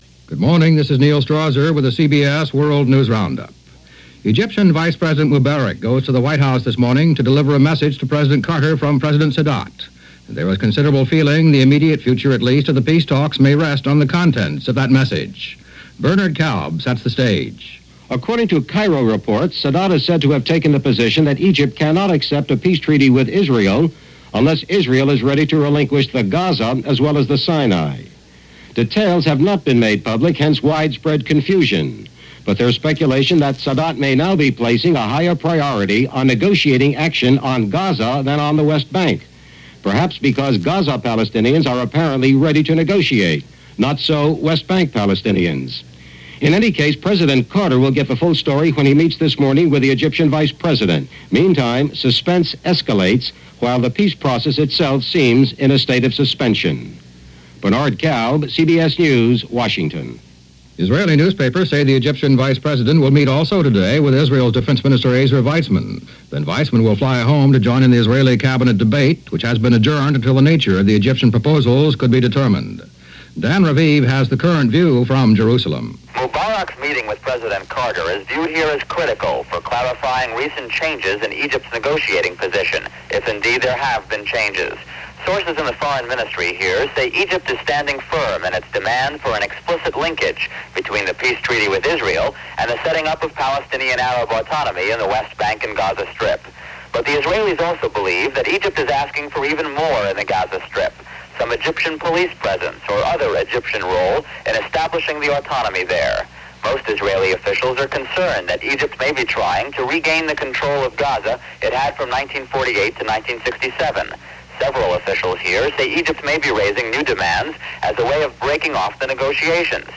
And that’s a small slice of what happened, this November 16, 1978 as heard on The CBS World News Roundup.